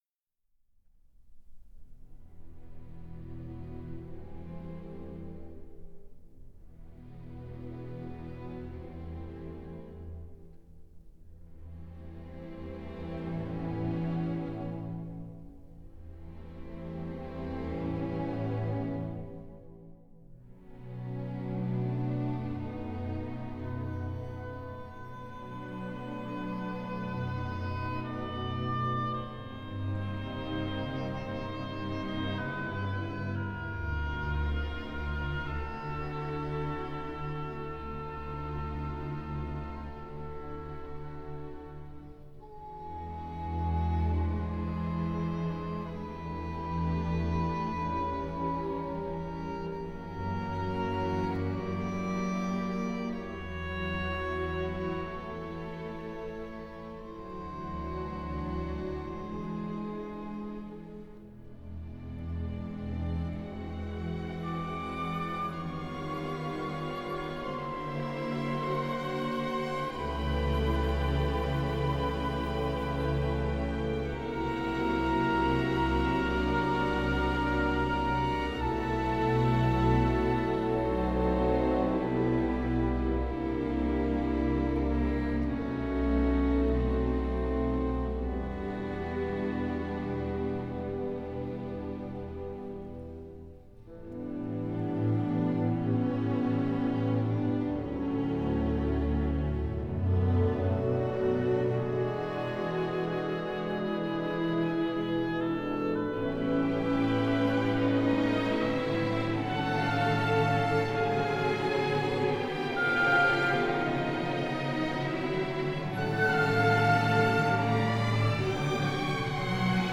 Comentario sinfónico al poema de Pedro Prado.